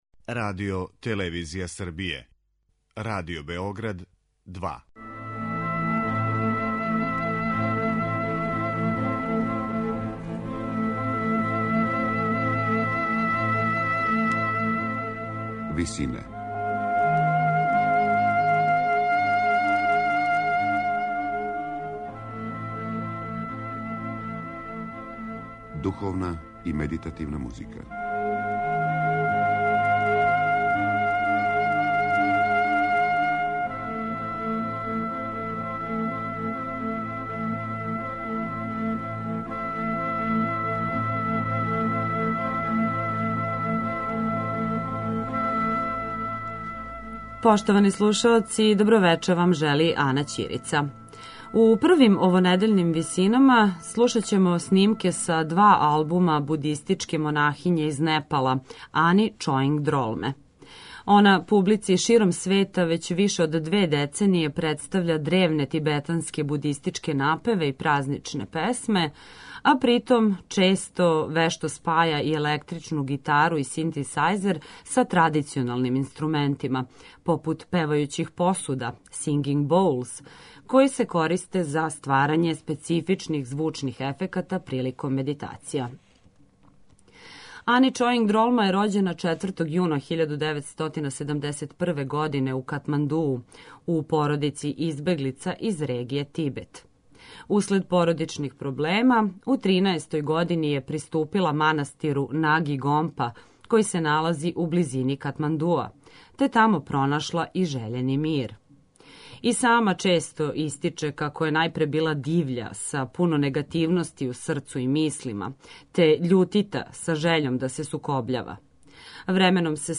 древни тибетански напеви.
електричну гитару и синтисаjзер
singing bowls